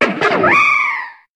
Cri de Darumarond dans Pokémon HOME.